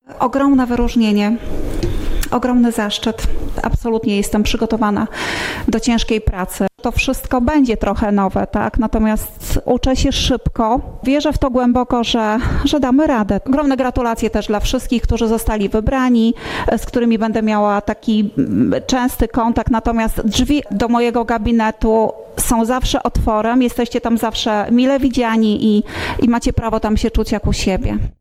Liczę na wsparcie w pełnieniu nowej funkcji – mówiła Anna Gawrych, nowo wybrany wicestarosta łomżyński podczas pierwszej sesji Rady Powiatu VII Kadencji.